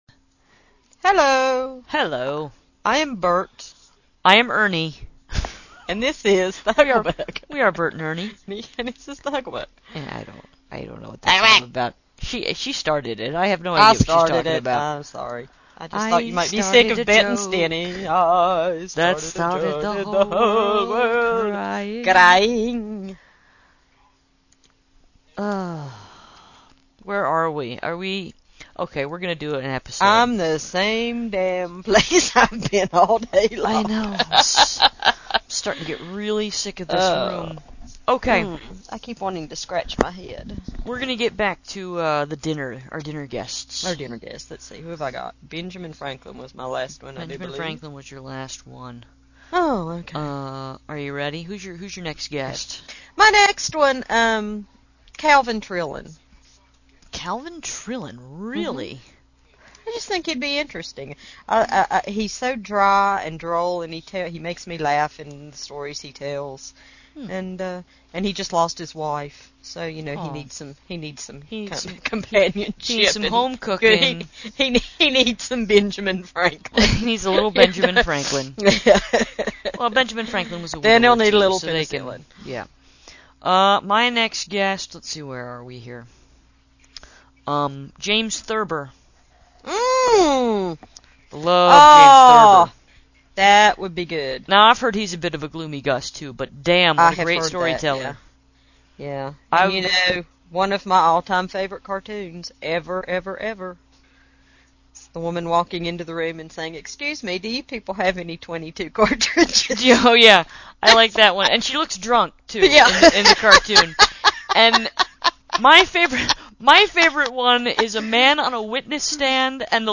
Also, I’m not sure how you sounded 14 hours ago, but y’all sound pretty rummy right now….